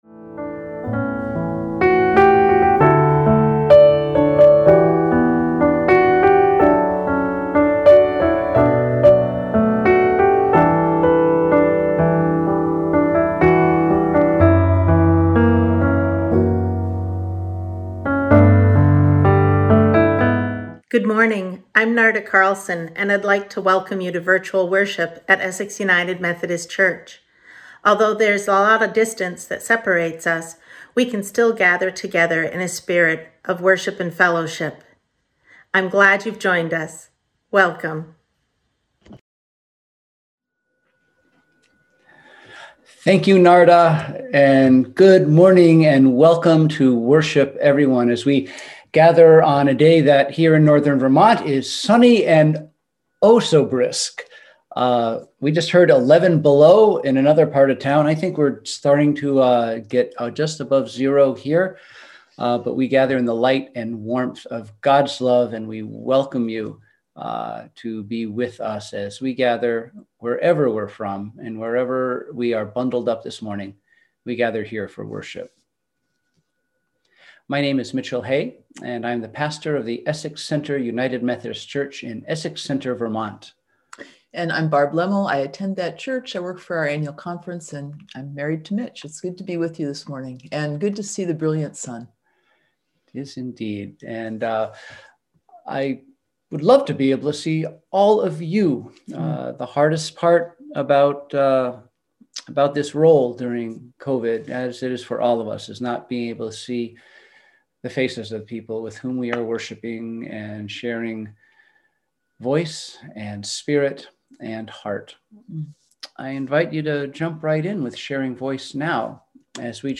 We held virtual worship on Sunday, January 31, 2021 at 10:00am!